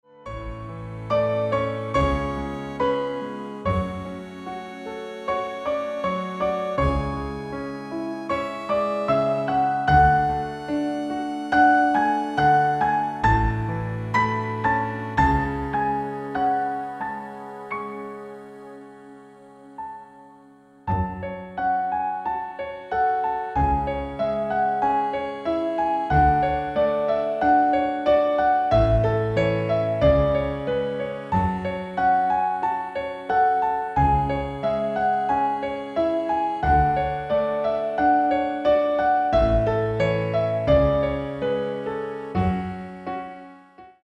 GM-Only MIDI File Euro 8.50
Demo's zijn eigen opnames van onze digitale arrangementen.